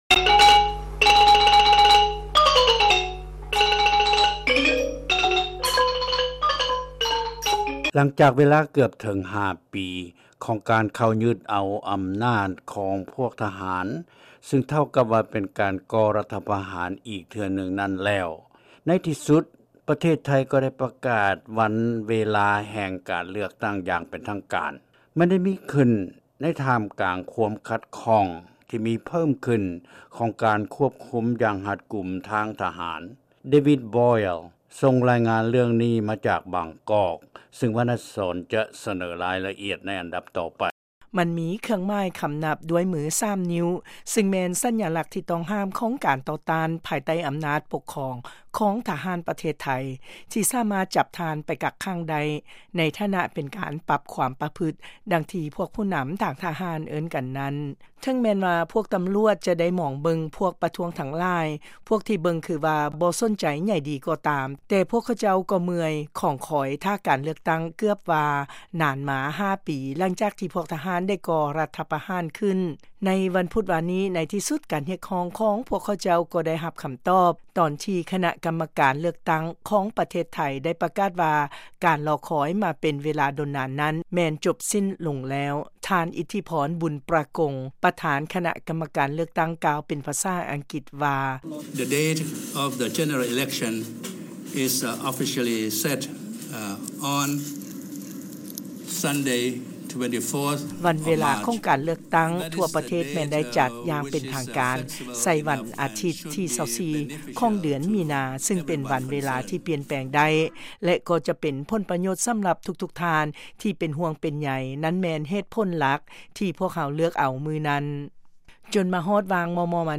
ລາຍງານການເລືອກຕັ້ງຂອງປະເທດໄທ ທີ່ຈັດໃສ່ວັນທີ 24 ມີນາ